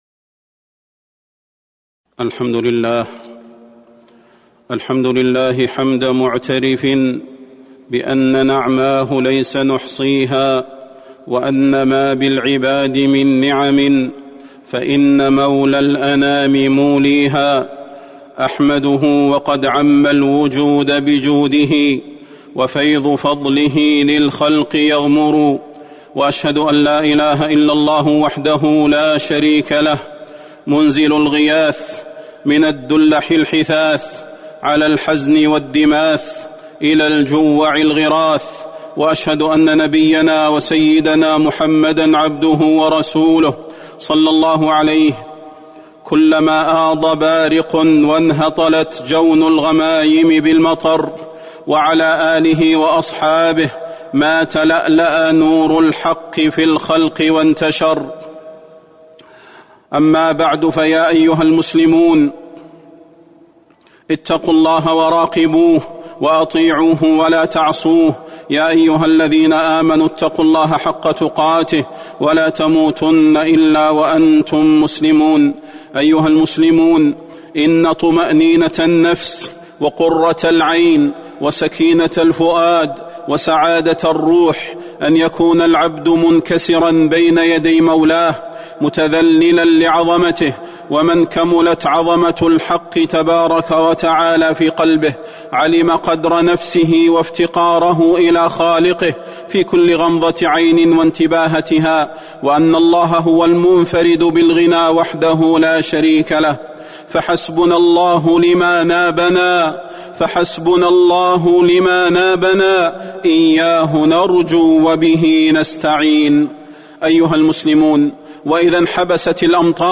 خطبة الاستسقاء - المدينة - الشيخ صلاح البدير